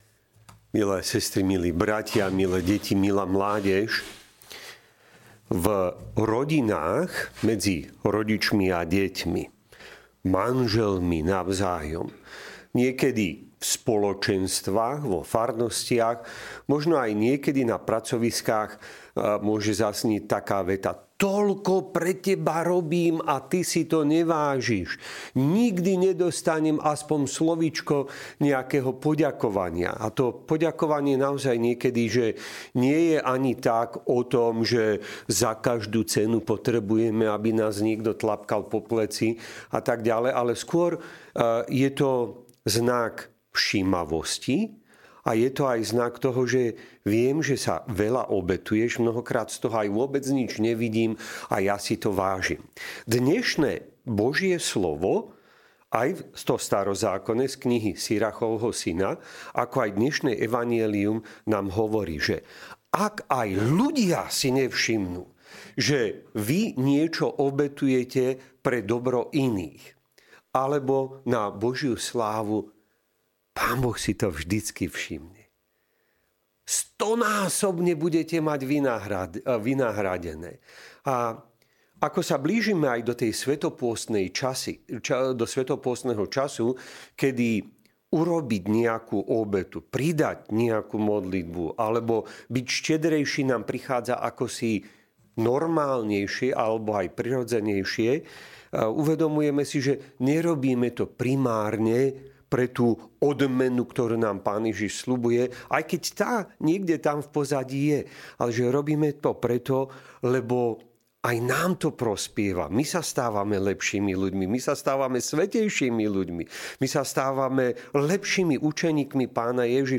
Podcasty Kázne BOH VIDÍ NAŠE OBETY